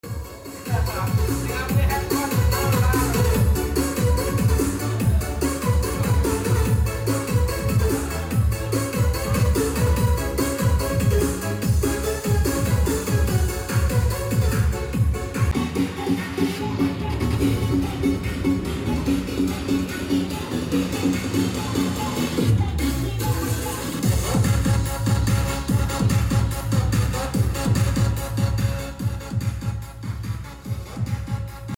Check Sound Speaker 10inch Aktif sound effects free download